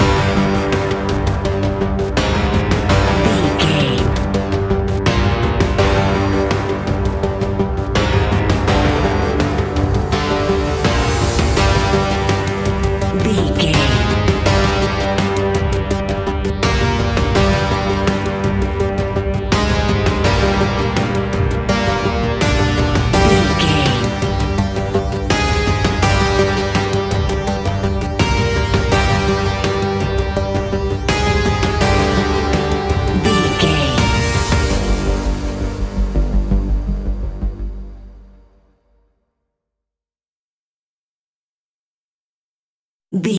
A great piece of royalty free music
In-crescendo
Aeolian/Minor
ominous
dark
eerie
ticking
electronic music
Horror Synths